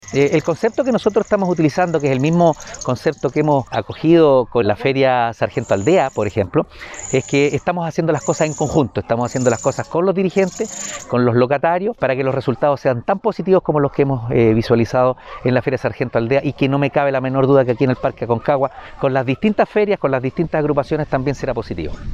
Alcalde-Oscar-Calderon-Sanchez-1-1.mp3